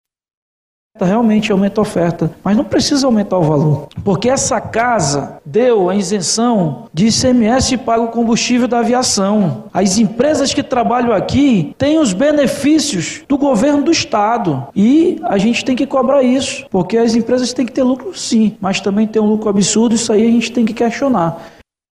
Durante sessão plenária na Aleam relatou que no período da festa dos bois Caprichoso e Garantido é mais barato ir para Flórida, nos Estados Unidos, já que o valor da passagem de ida custa em média R$2.297 cada trecho.
deputado-Roberto-Cidade-.mp3